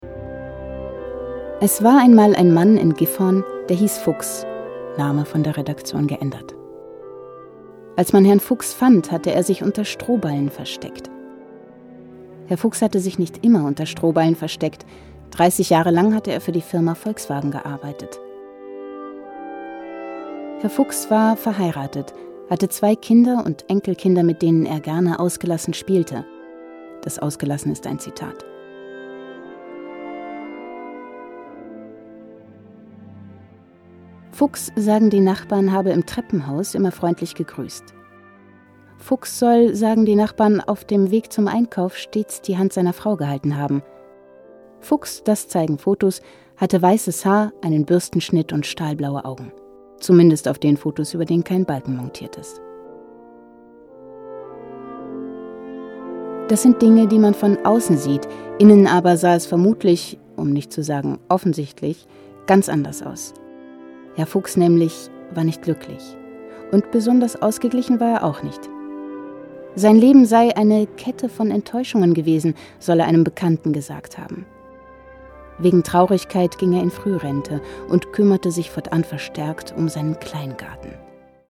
warm, lieblich, frisch, lebendig, hell, überzeugend, leicht
Sprechprobe: eLearning (Muttersprache):